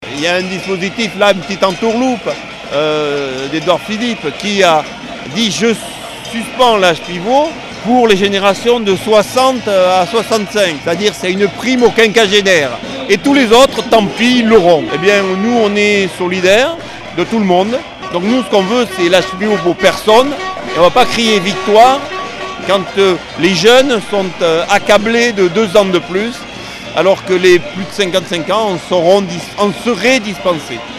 Tenus à bonne distance du château d'Henri IV par l'imposant dispositif de sécurité, une seule solution lundi 13 janvier au soir à Pau : faire un maximum de bruit.